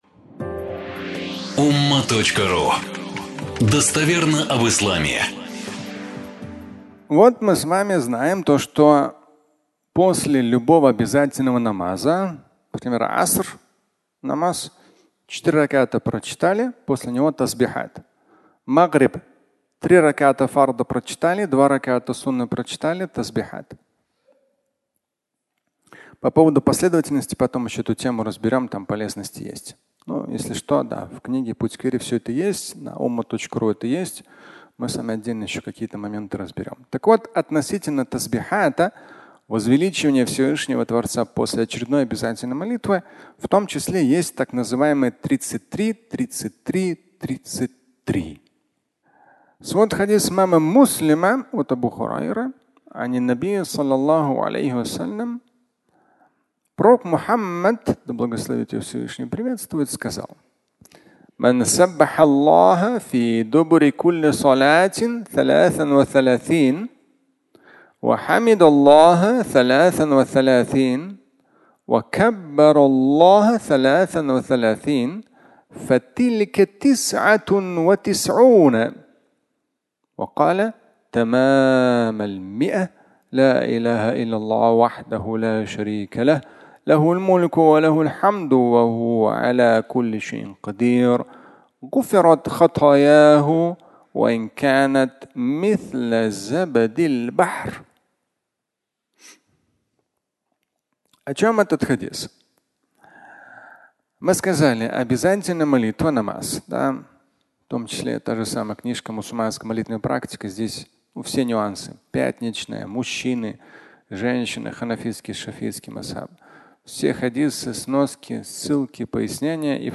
33+33+33 (аудиолекция)